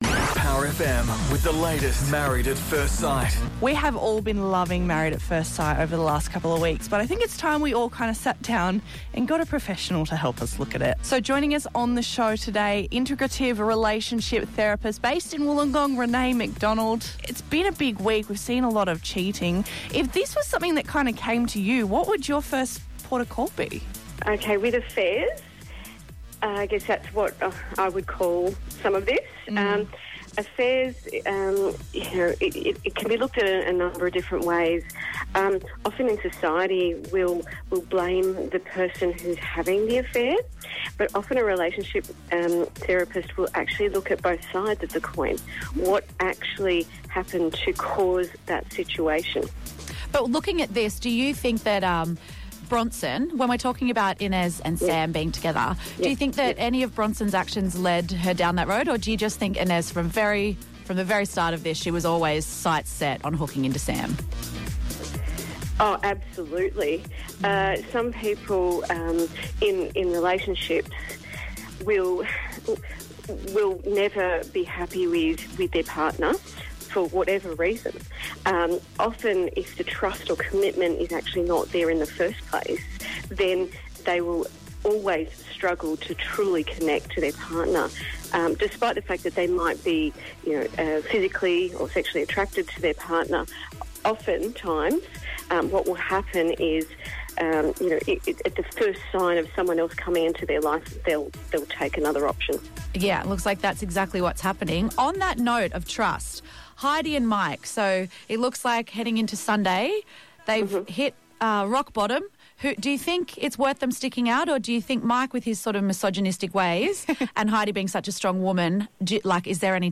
Have a listen here to my segment from PowerFM’s breakfast radio segment about the Married At First Sight show (MAFS) from 22 February, 2019.